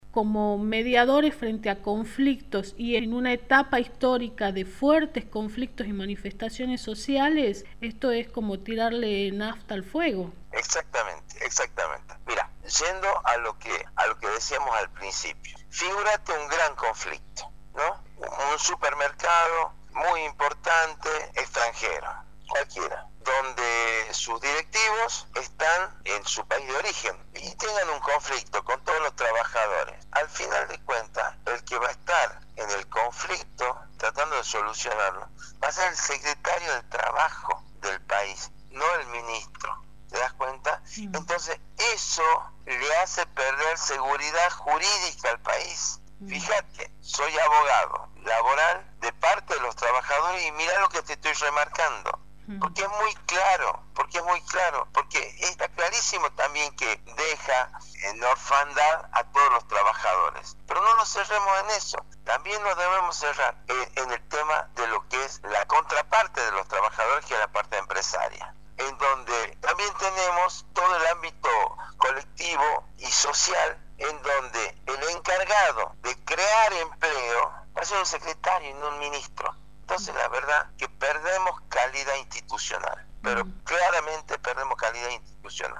LA ENTREVISTA: